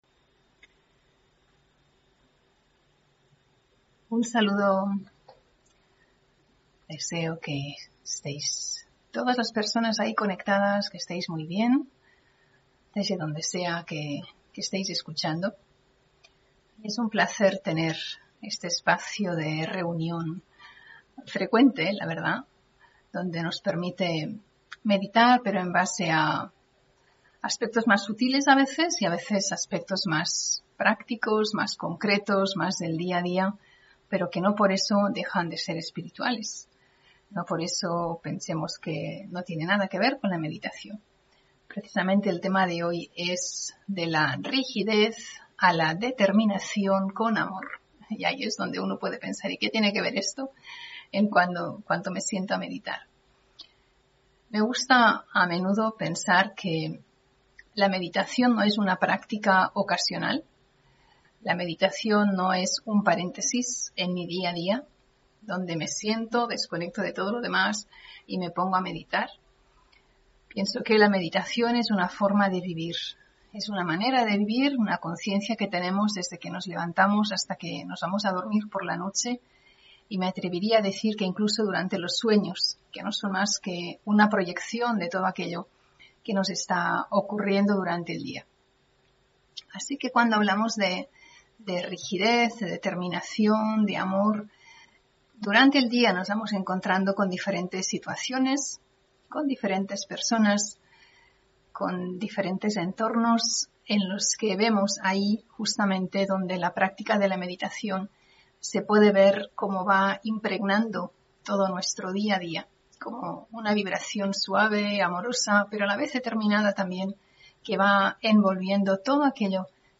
Meditación y conferencia: De la rigidez a la determinación con amor (28 Julio 2022)